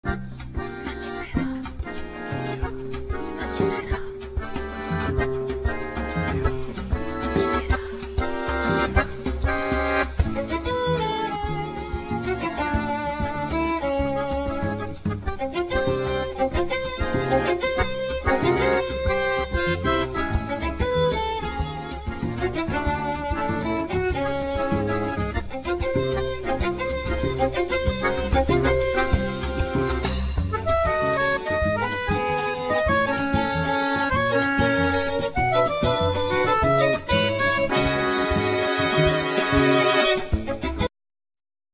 Accordeon,Vocals
Violin,Viola,Vocals
Cello
Drums,Percussions